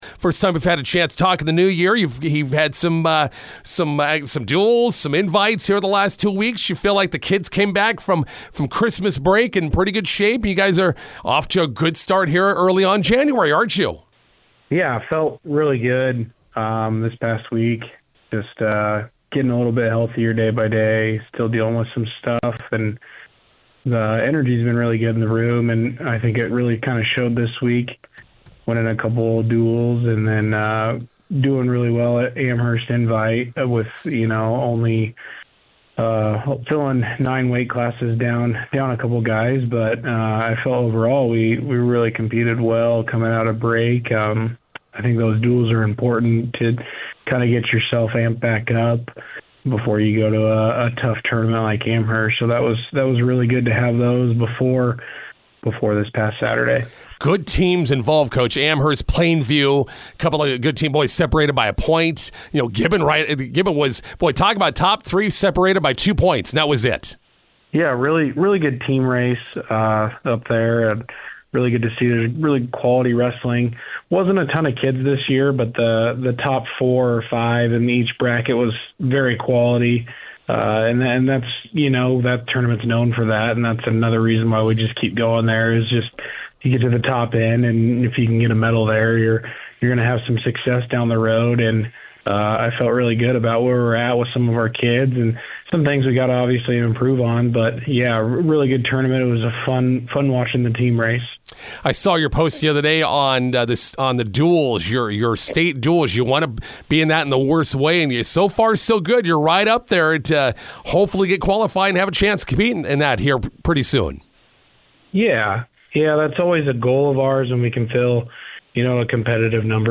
INTERVIEW: Cambridge wrestling moves into the Class D top ten state dual point standings.